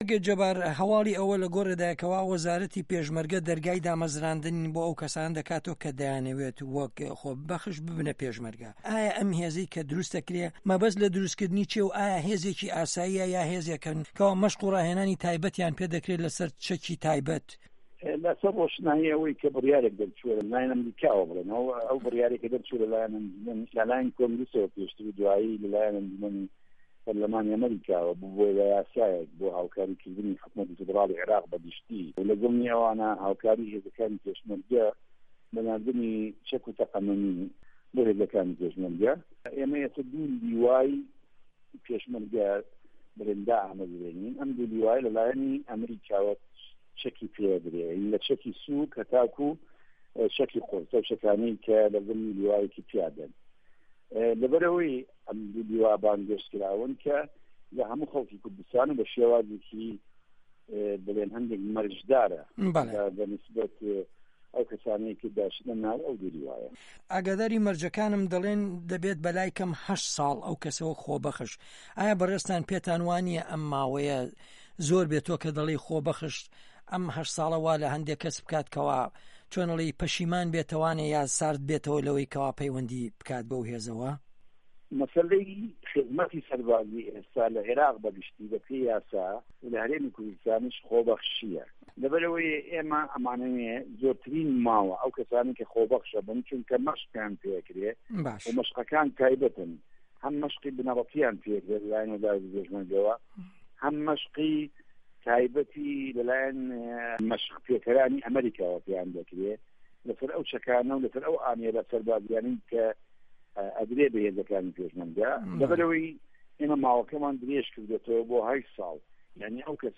وتوێژ له‌گه‌ڵ جه‌بار یاوه‌ر